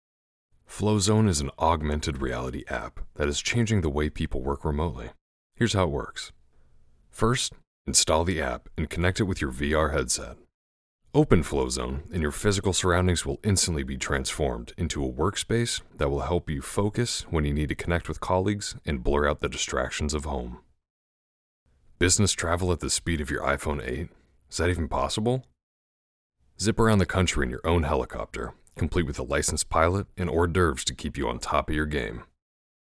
Online Ad Demo
General American
Young Adult
Middle Aged